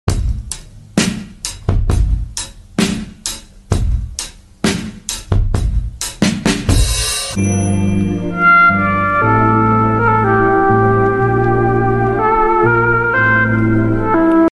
lo fi beats